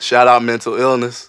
Shoutout.wav